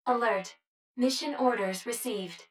153_Orders_Recieved.wav